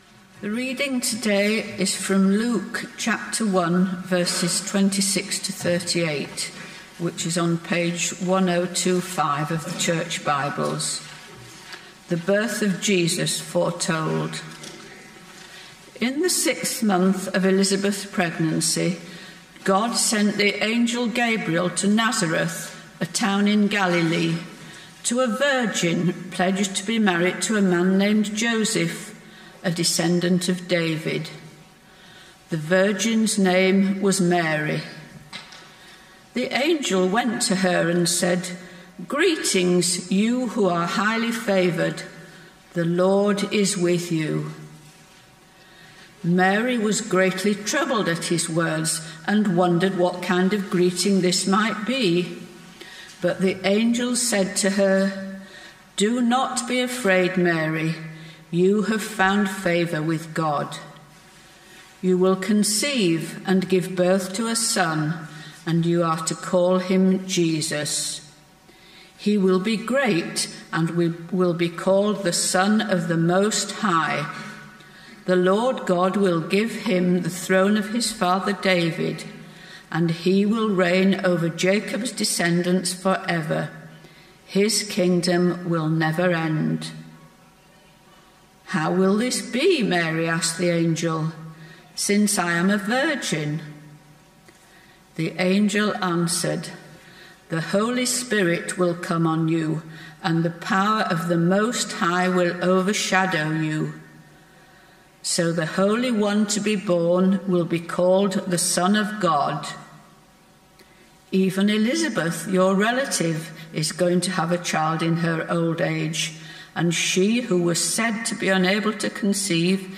Sermon points The Adventure of Christmas: Mary, Luke 1:26-38 Why submit like Mary?
Service Type: Sunday Morning